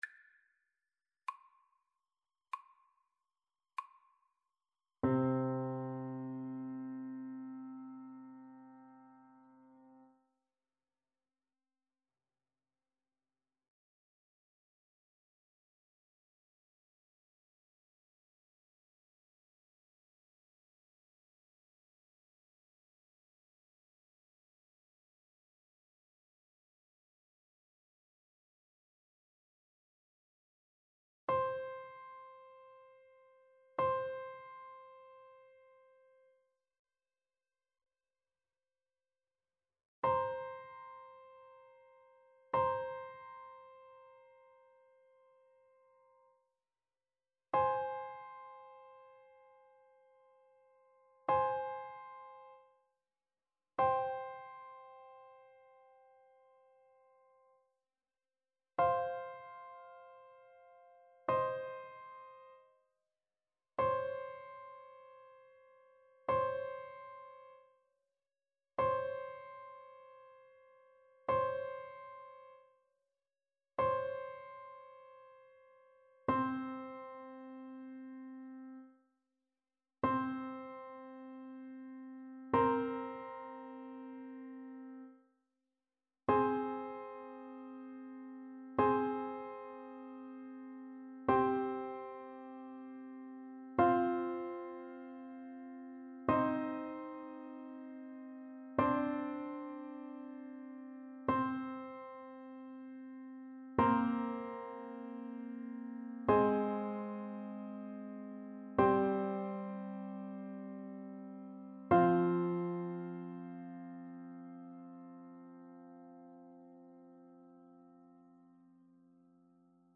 Lent =48